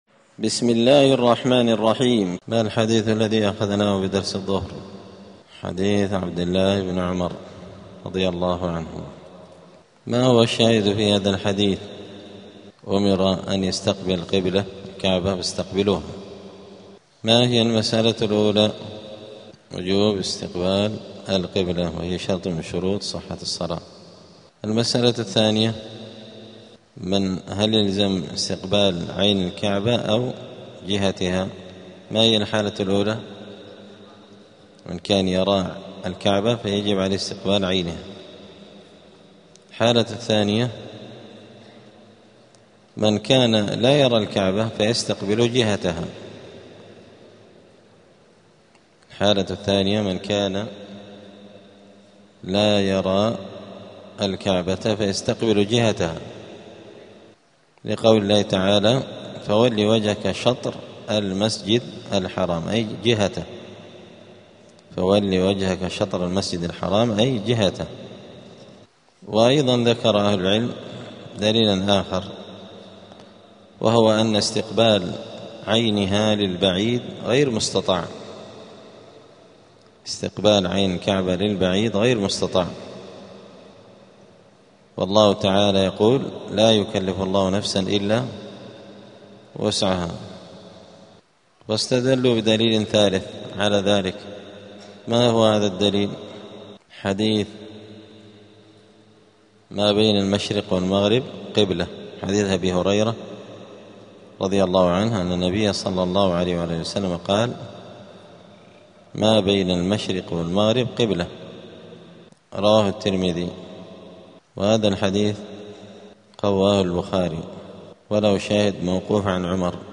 دار الحديث السلفية بمسجد الفرقان قشن المهرة اليمن
*الدرس الثالث والسبعون بعد المائة [173] باب استقبال القبلة {هل يلزم استقبال عين الكعبة أو جهتها}*